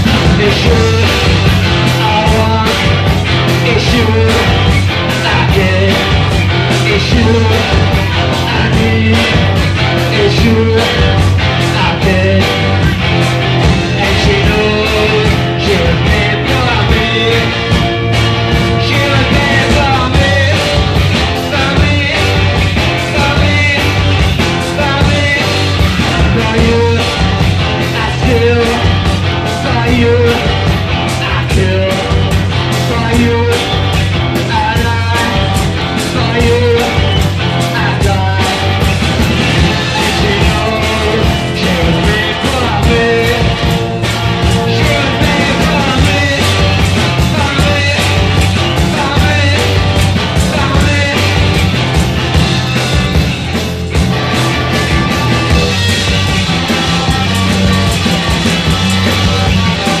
ROCK / 80'S/NEW WAVE. / NEW WAVE / GARAGE PUNK
80'Sガレージ/サイケのオムニバス好盤！